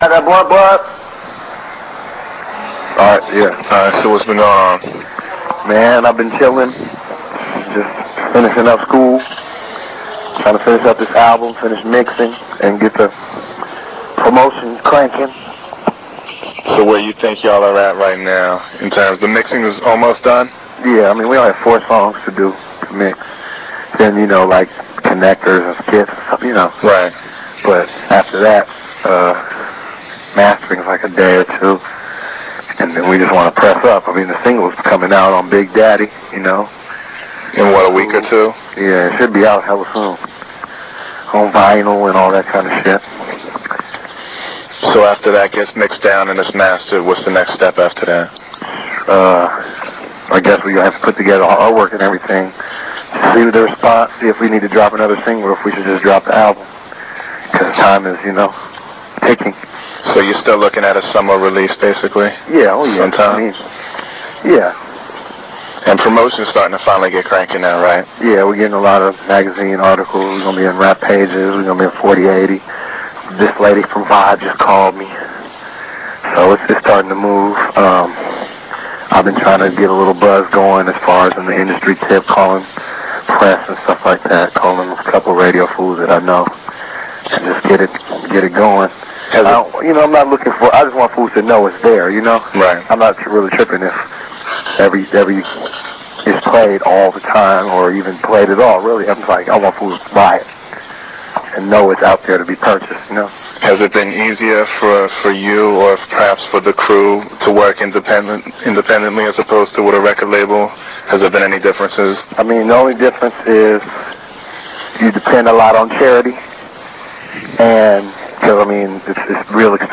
Interview with Tajai of Souls of Mischief / Hieroglyphics (June 1997)
Every week or so Tajai and I would speak on the phone and I would get the latest happenings with the Hiero Crew and write about it on Hiero Online.
The interview was originally streamed in RealAudio; a technology that delivered streaming audio to web browsers for the very first time.
tajai-souls-of-mischief-interview-june-1997.mp3